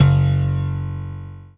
PIANO5-02.wav